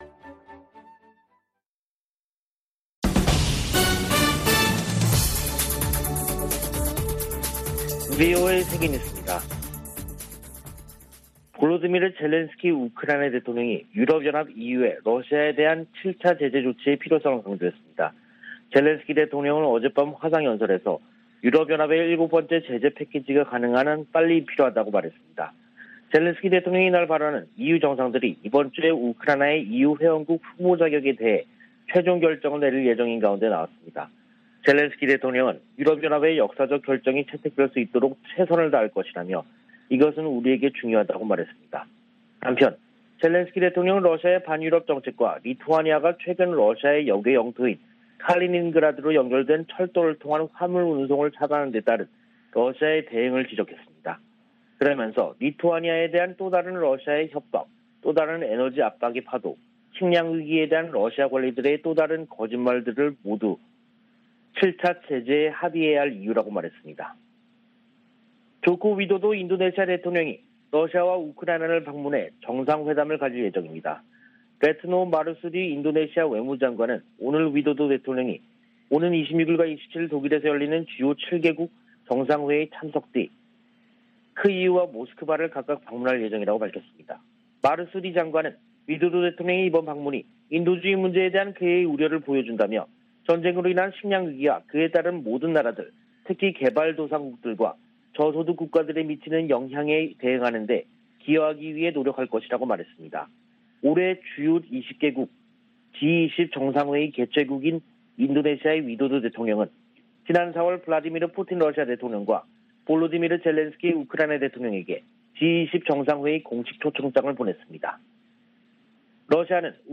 VOA 한국어 간판 뉴스 프로그램 '뉴스 투데이', 2022년 6월 22일 3부 방송입니다. 미 국무부가 미한 상호방위 역량 강화와 대북 대화 추구 등 주한 미국대사관의 향후 4년 목표를 공개했습니다. 미국 정부가 한반도를 제외한 모든 지역에서 대인지뢰 사용과 생산 등을 금지한다고 밝혔습니다. 미 국방부가 북한의 탄도미사일 대응에 적합하다는 평가를 받고 있는 해상요격기 SM-3 미사일 생산 업체를 선정했습니다.